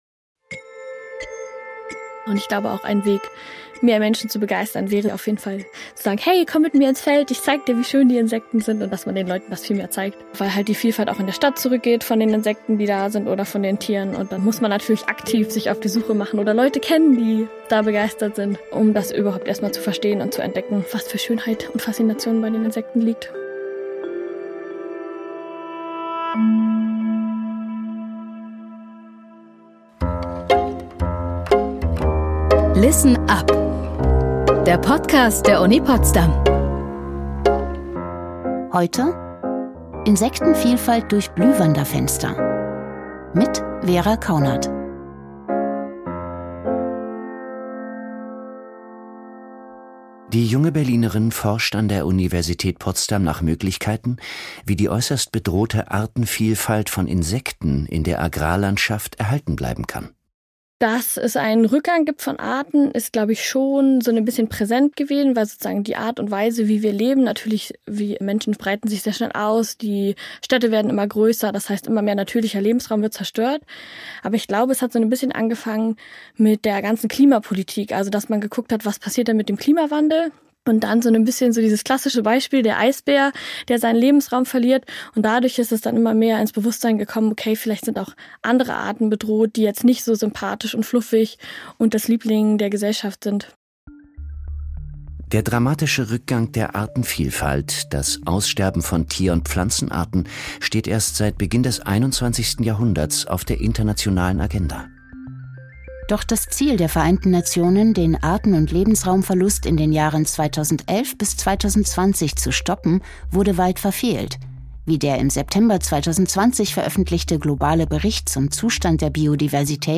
Das Forschungsprojekt ist im Jahr 2022 gestartet. Im Podcast spricht sie nicht nur über ihre Promotion, sondern auch über weitere Projekte und Ideen für mehr Aufmerksamkeit zum Erhalt der Artenvielfalt.